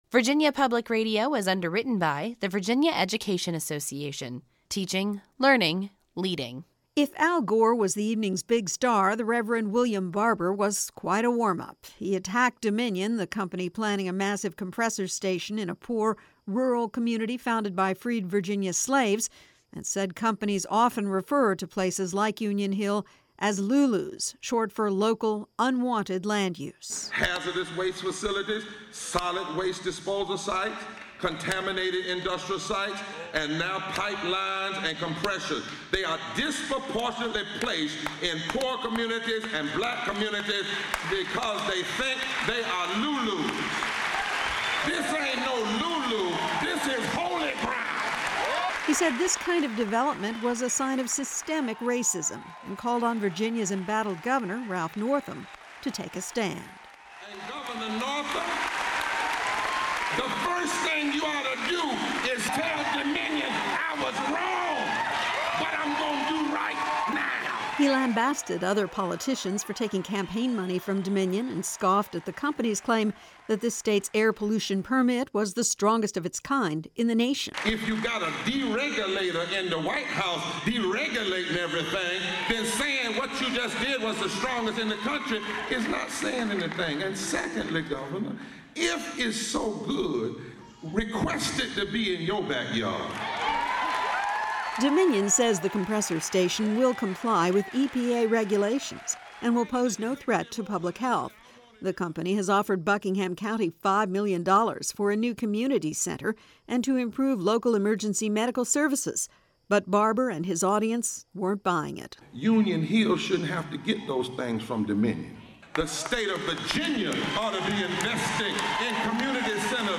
It was a combination protest, spiritual revival and celebration as about 800 people packed the gym at a middle school in Buckingham County last night to hear from environmental activist Al Gore and political activist William Barber. They had come to oppose the Atlantic Coast Pipeline and construction of a massive compressor station in the historic black community called Union Hill.